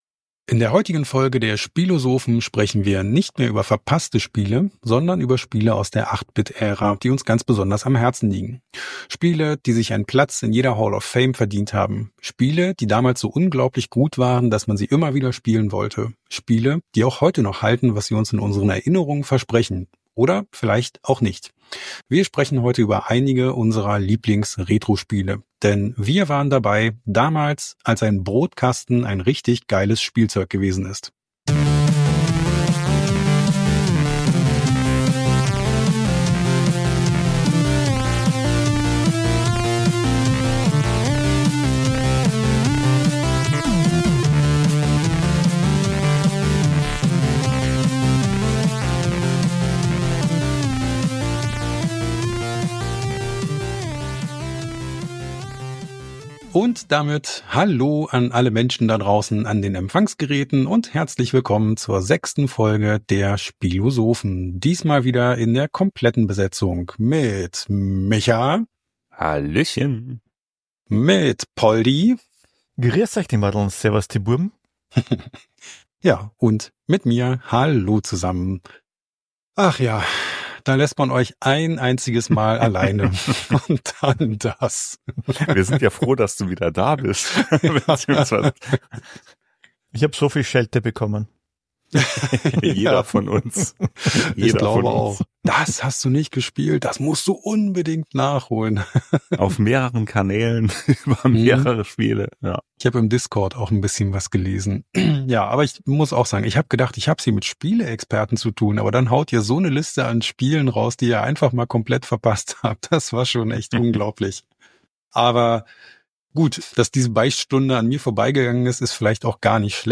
Zwei Jungs aus den 80ern sprechen über alte Computerspiele.